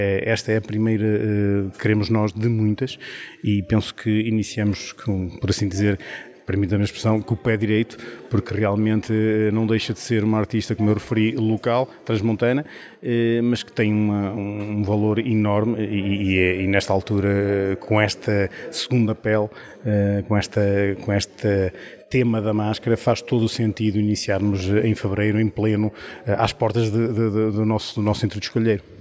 O autarca destacou ainda a aposta do município na dinamização cultural do Centro Cultural, com exposições regulares e valorização de artistas da região: